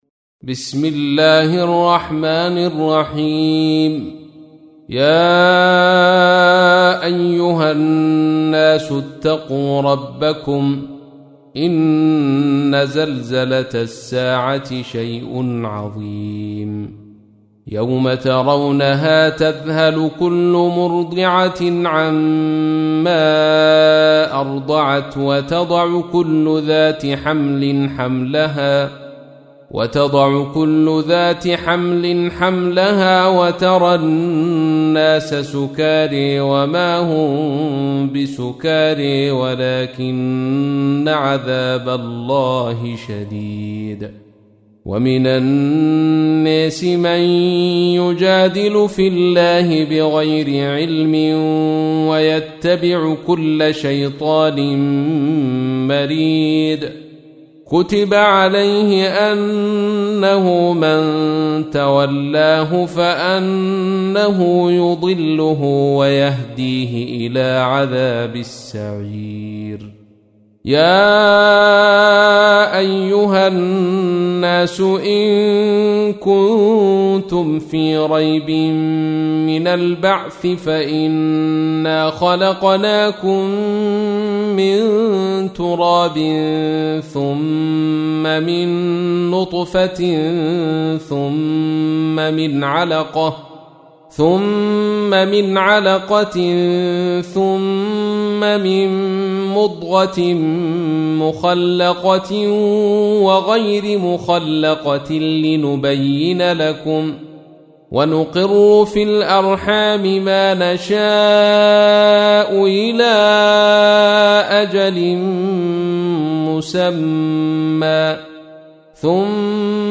تحميل : 22. سورة الحج / القارئ عبد الرشيد صوفي / القرآن الكريم / موقع يا حسين